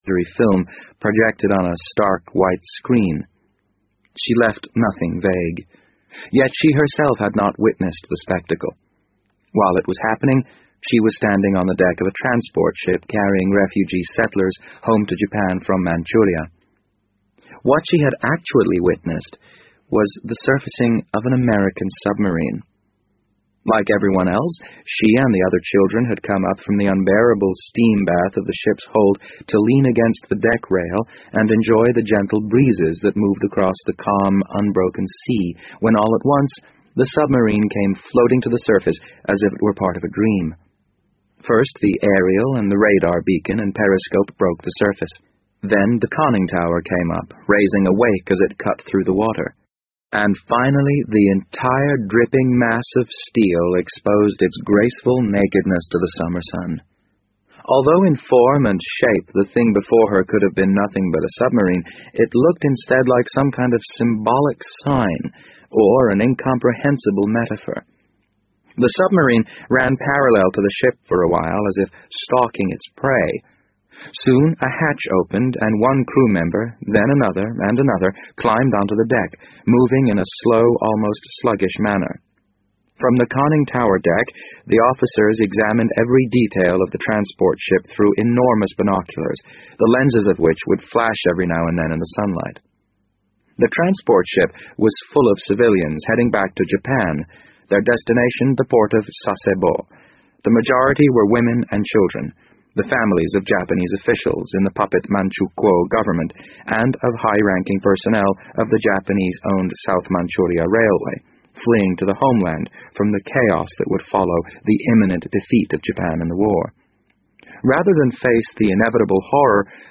BBC英文广播剧在线听 The Wind Up Bird 010 - 12 听力文件下载—在线英语听力室